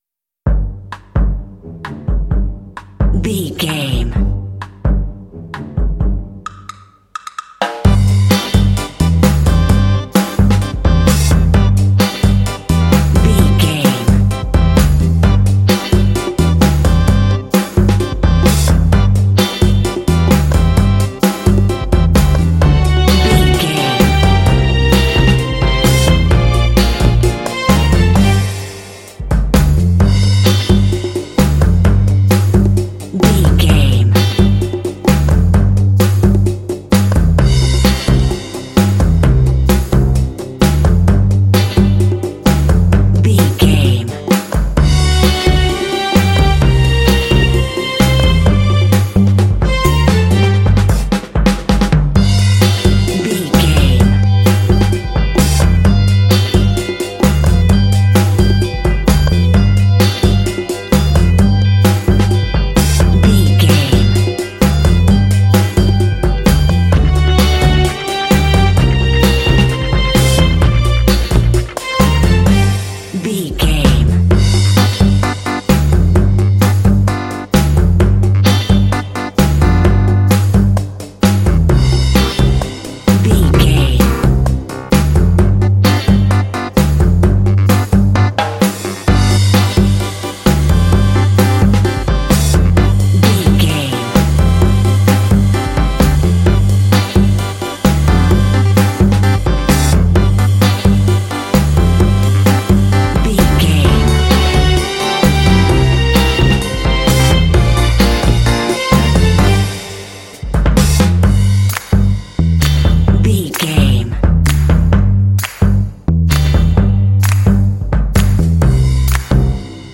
Uplifting
Aeolian/Minor
funky
smooth
groovy
driving
happy
bright
drums
brass
electric guitar
bass guitar
organ
percussion
conga
rock
Funk